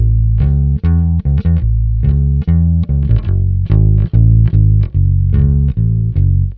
Twisting 2Nite 1 Bass-A.wav